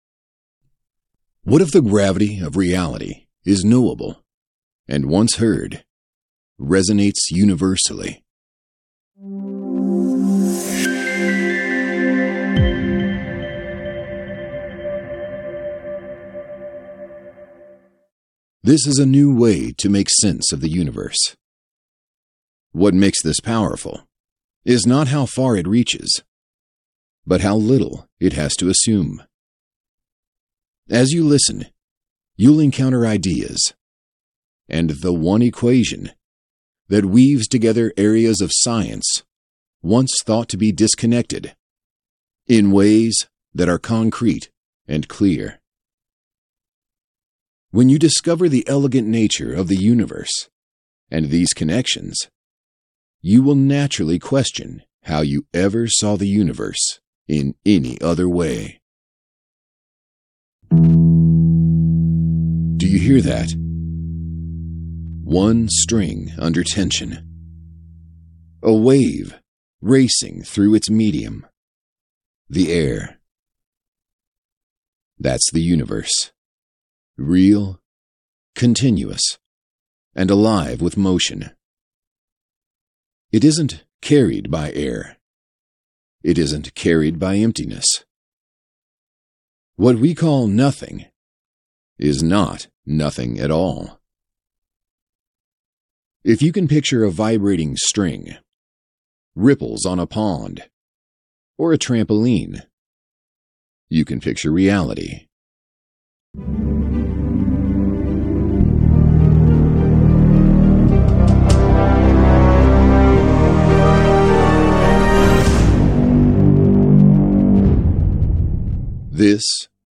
UMH_AudioBook_Compressed.m4a